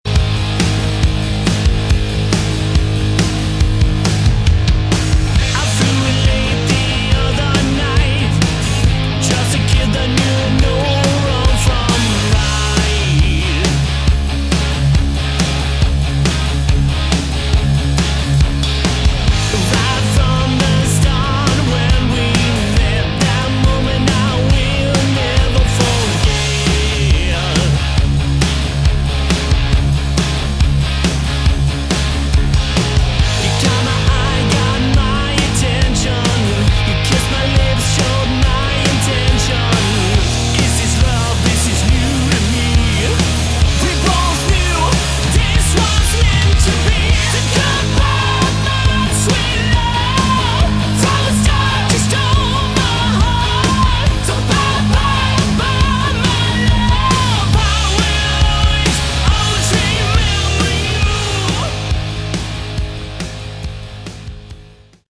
Category: Sleaze Glam